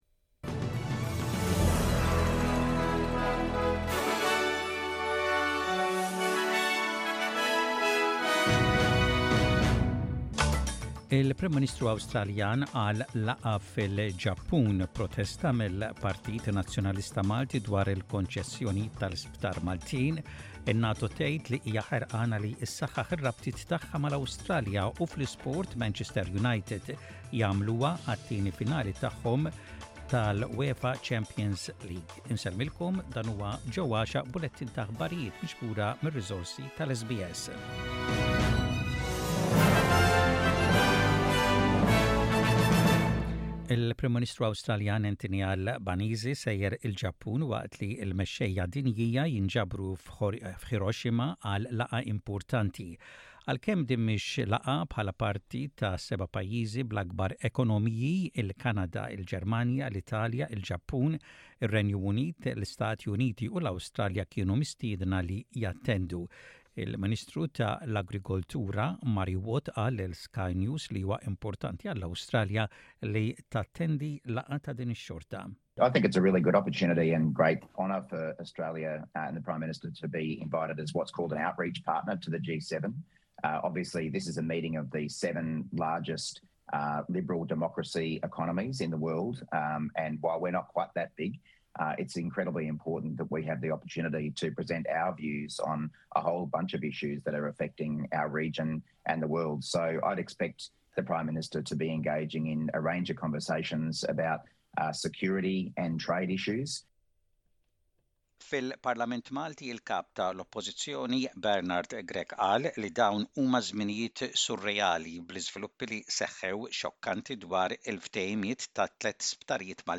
SBS Radio | Maltese News: 19/05/23